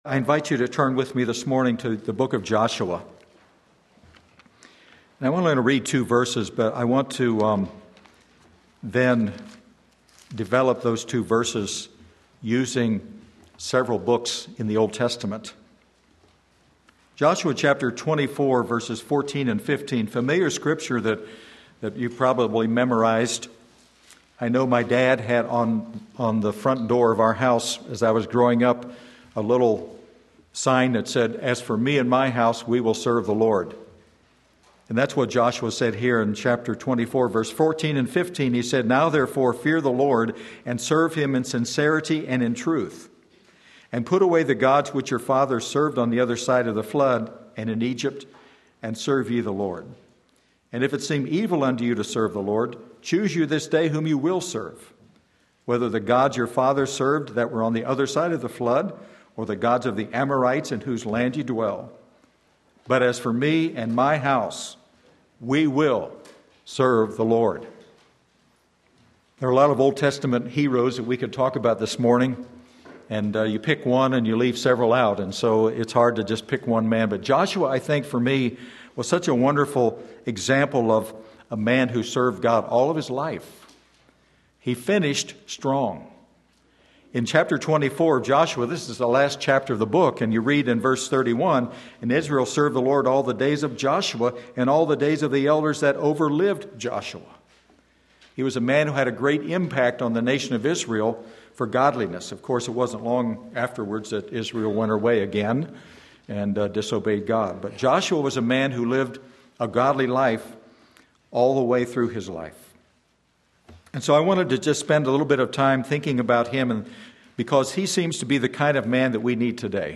Sermon Link
and Commissioned by God Joshua 24:14-15 Sunday Morning Service